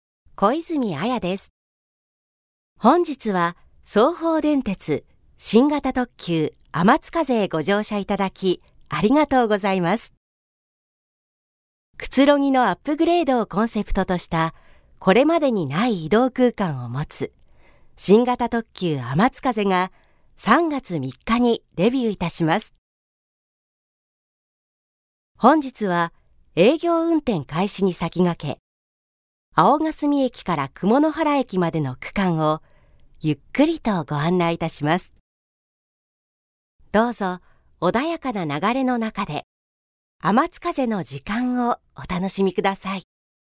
• 透明感ある正統派
• 音域：高～中音
• 声の特徴：：さわやか、落ち着き、正統派